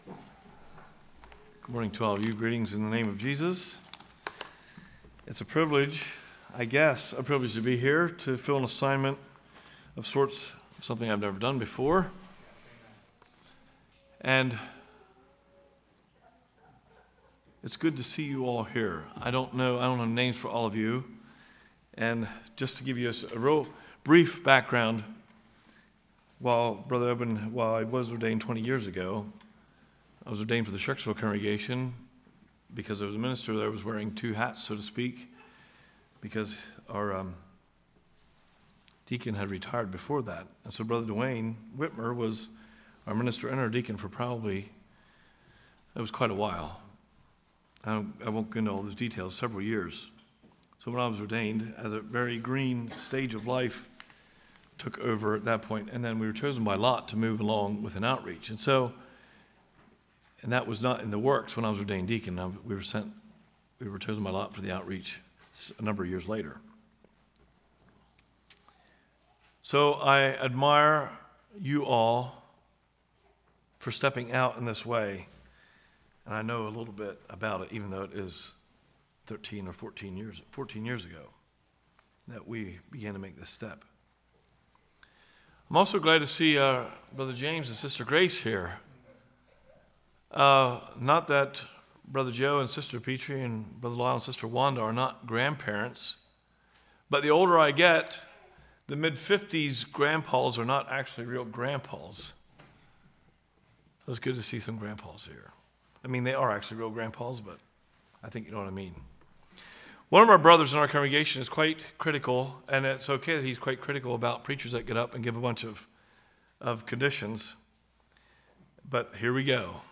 Qualification message for a deacon.